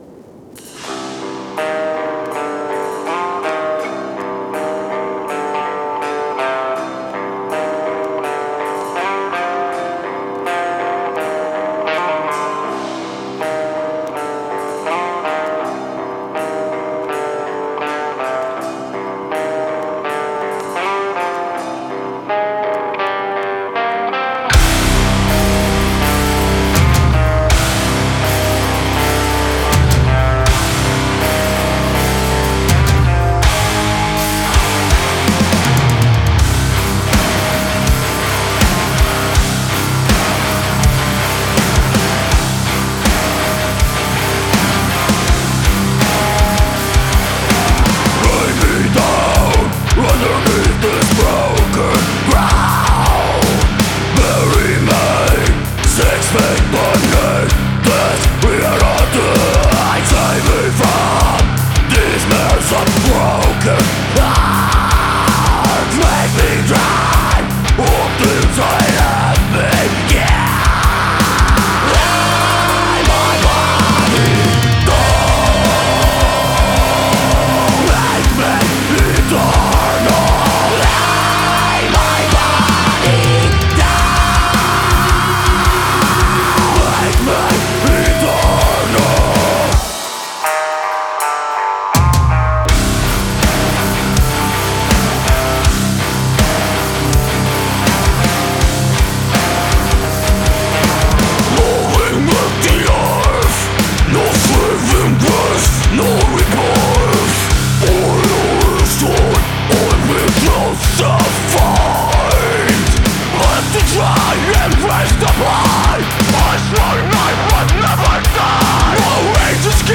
an emotional powerhouse of melancholy and denial